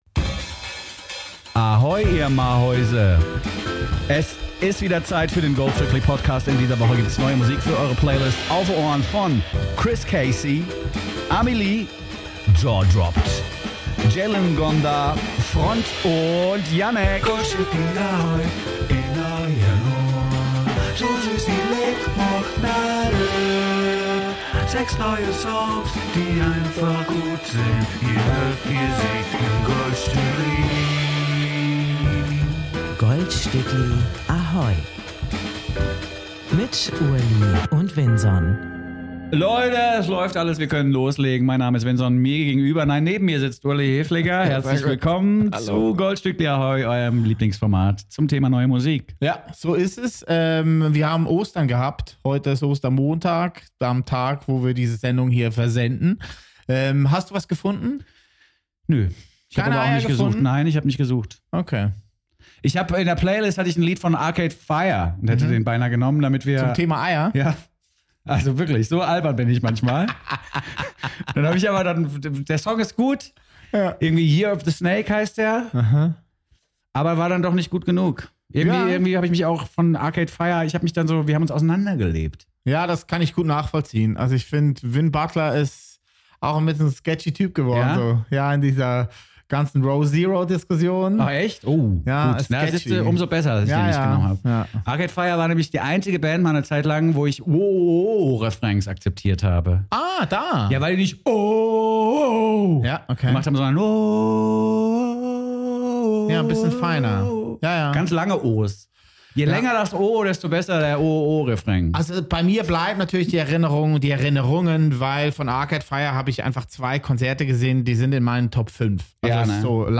Jeweils montags ab 14 Uhr auf AHOY Radio und anschliessend überall da, wo es Podcasts gibt.
Jede Woche besprechen die beiden Profimusikhörer sechs brandneue Songs und präsentieren zusätzlich ein „OLDstückli“ im GOLDSTÜCKLi, so dass auch die Klassiker des Pop nicht in Vergessenheit geraten.